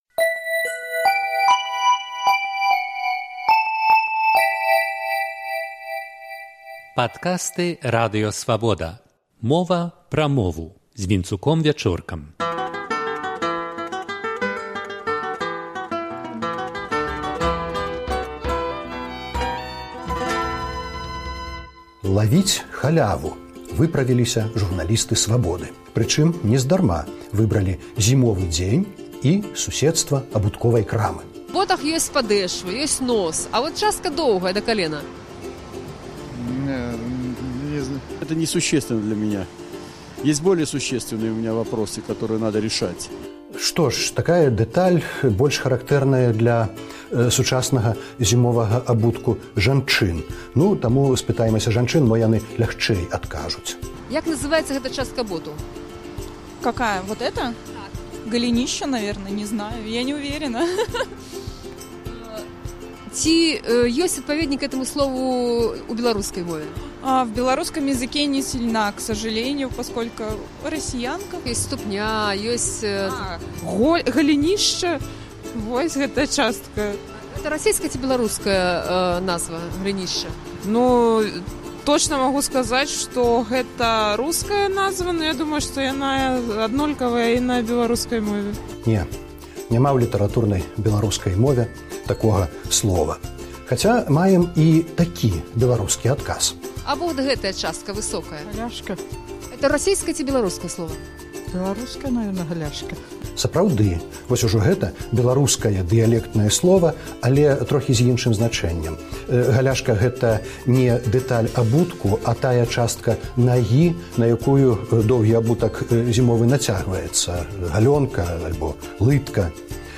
Ці ловяць халяву беларускія студэнты? Вулічнае апытаньне.